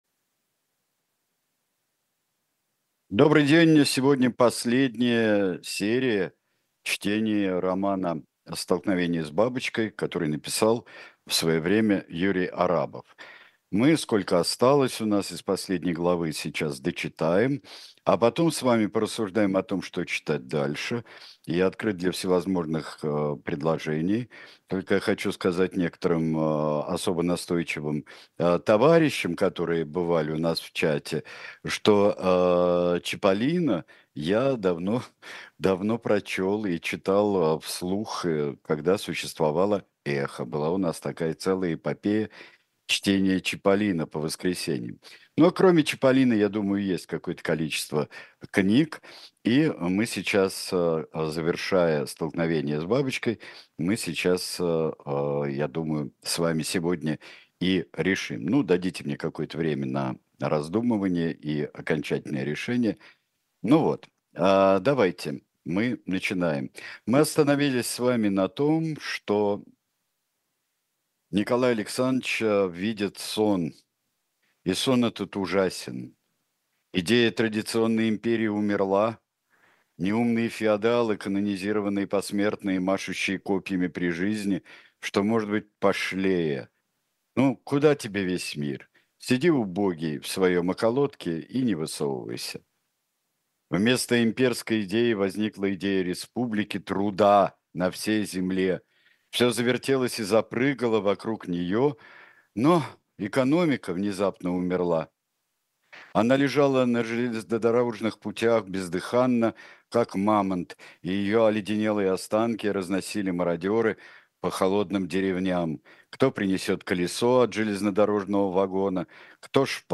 Сергей Бунтман читает роман Юрия Арабова
stolknovenie_s_babochkoj_—_chast_14_chitaet_sergej_buntman.mp3-online-audio-convert.com_.mp3